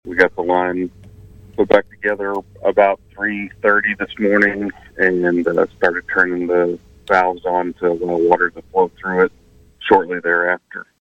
Department Director Dean Grant joined KVOE’s 8:05 am newscast to update residents Monday: